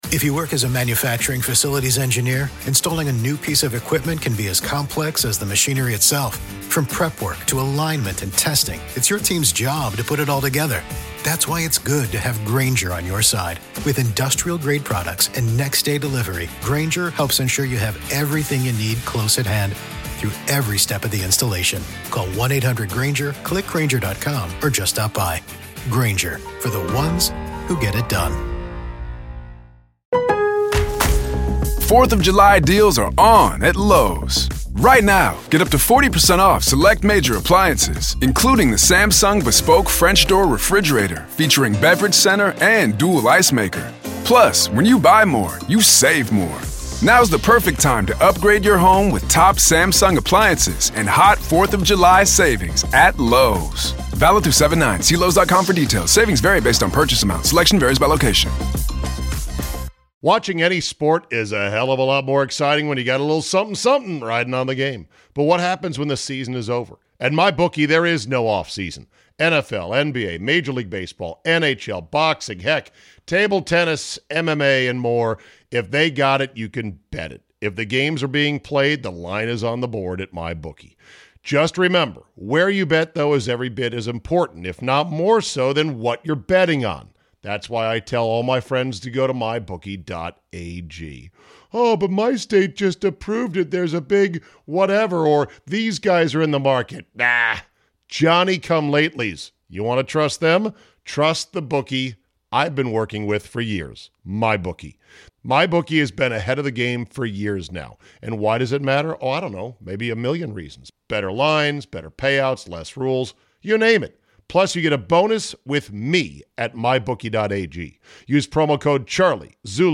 1 The Breakfast Club BEST OF 2(Teddy Swim, Nina Parker & KevOnStage, And Leon Thomas Interview) 1:30:33 Play Pause 5h ago 1:30:33 Play Pause Play later Play later Lists Like Liked 1:30:33 Best of 2025- BEST MOMENTS - Teddy Swim, Nina Parker & KevOnStage, And Leon Thomas Interview. Older Men Dating Young Women Topic.